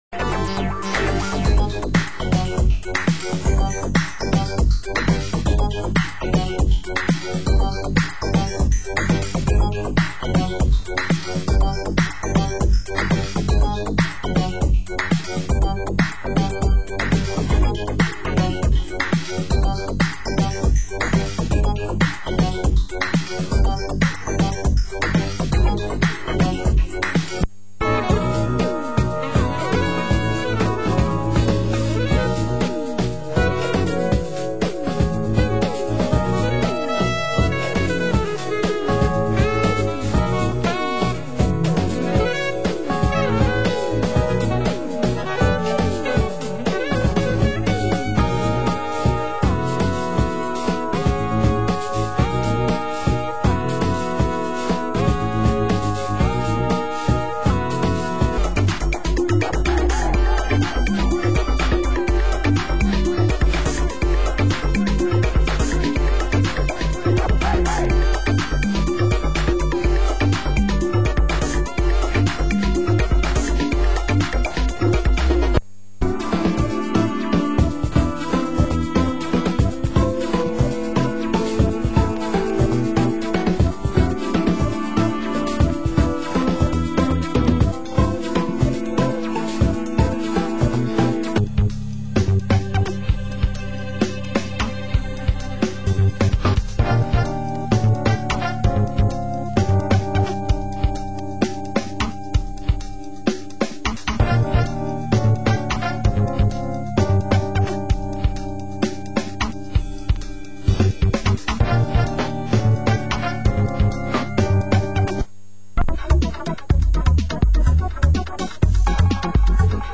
Format: Vinyl 12 Inch
Genre: Euro House